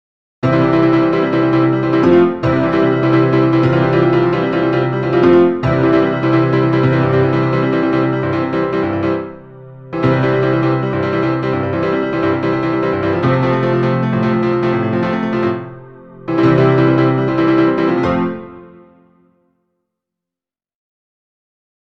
Zunächst ist das Ausganspattern zu hören, danach dann die Variationen, welche mit ChordPotion erzeugt wurden.
Piano:
chordpotion-piano-3.mp3